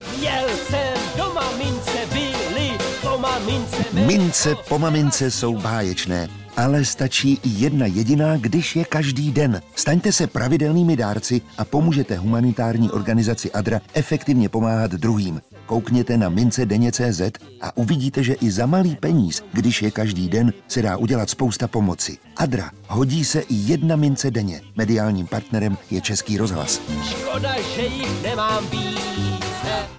audiospot, který vznikl díky skvělé spolupráci, s reklamní agenturou JWT, nahrávacím studiem AWR, panem Miroslavem Táborským, divadlem Semafor a Ochranným svazem autorů (OSA).